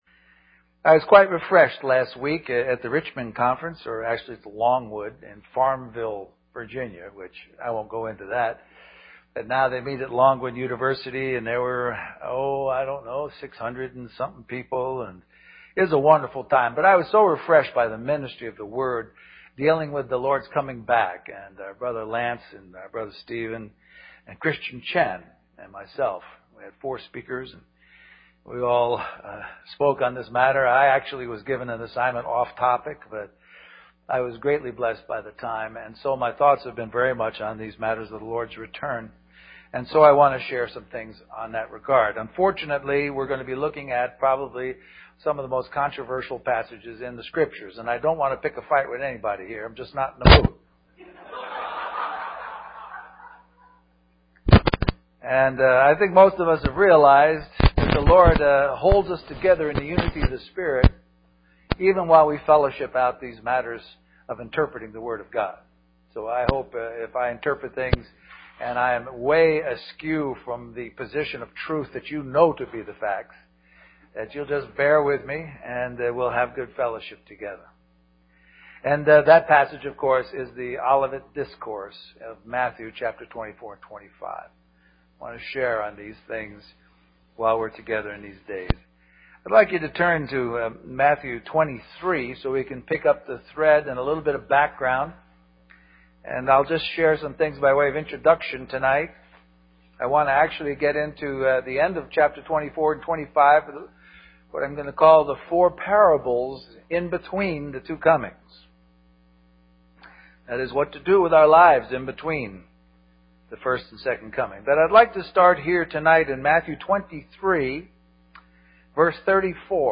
A collection of Christ focused messages published by the Christian Testimony Ministry in Richmond, VA.
Western Christian Conference